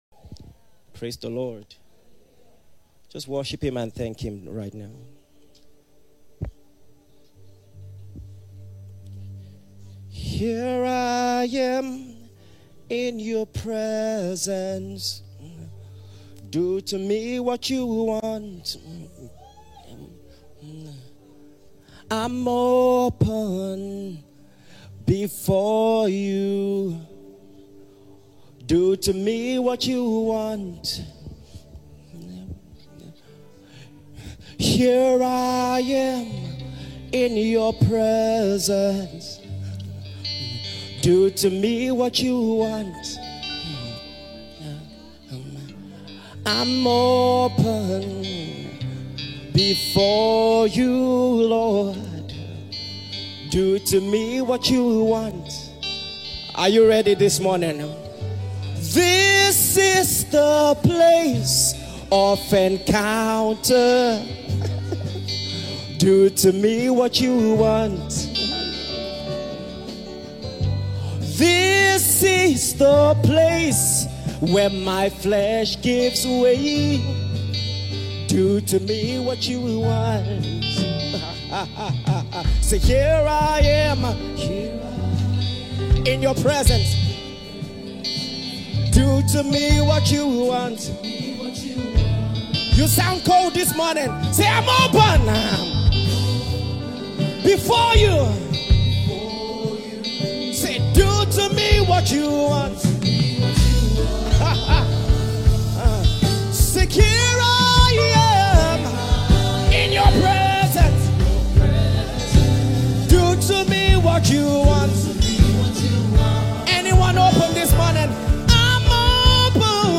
Download Sermon Catch Fire
Remain blessed as you listen to the wisdom from God preached through his servant.